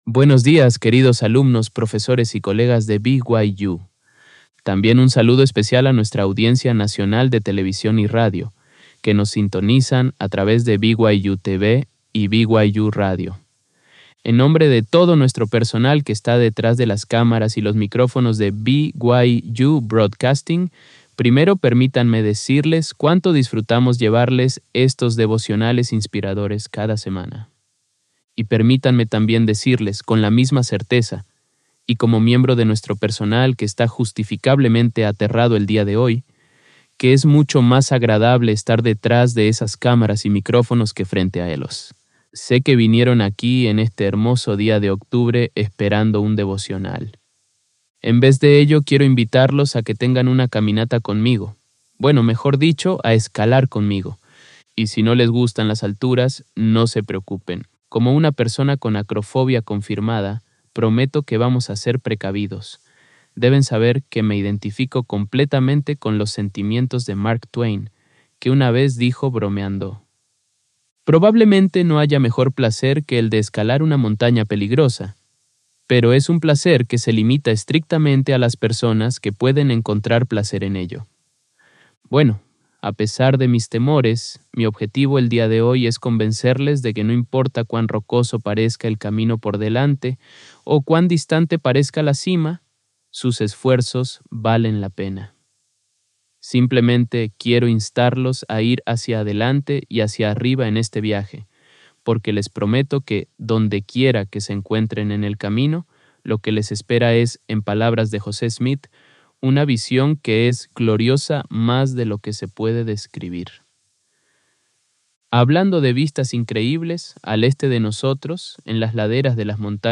En su devocional de BYU